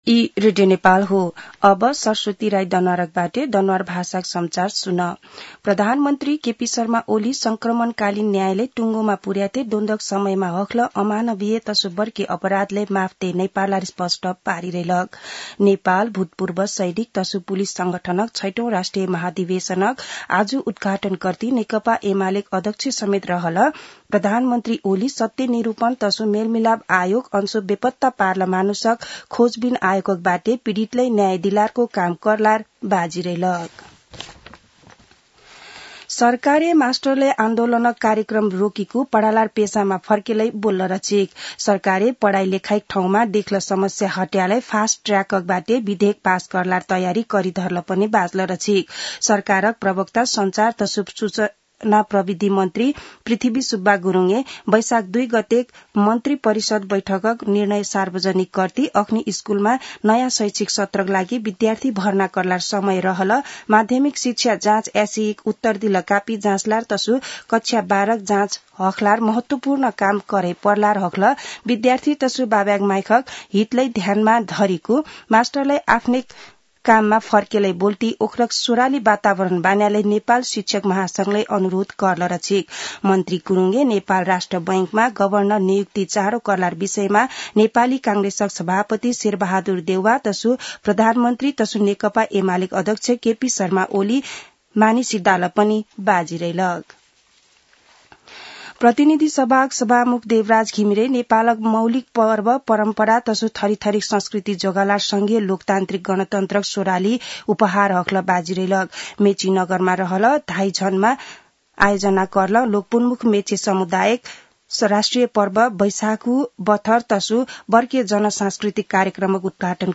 दनुवार भाषामा समाचार : ४ वैशाख , २०८२
danuwar-news-1-6.mp3